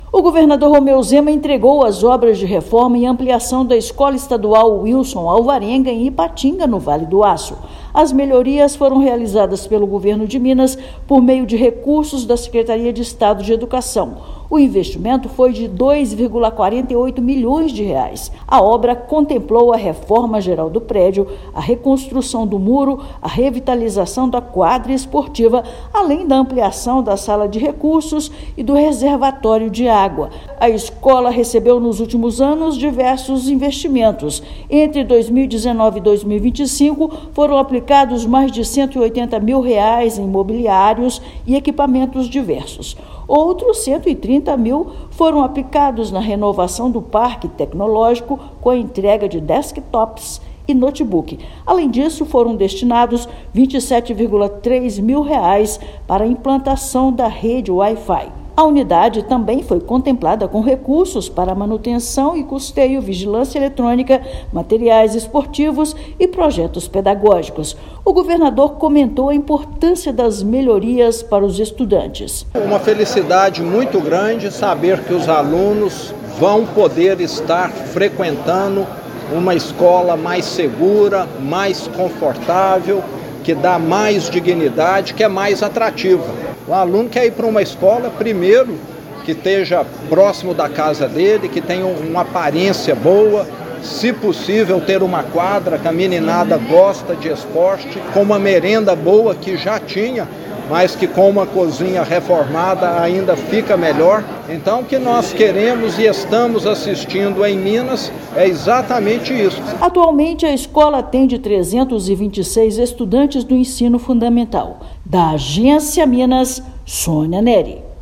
Investimento de mais de R$ 2,4 milhões melhora a infraestrutura para mais de 320 estudantes. Ouça matéria de rádio.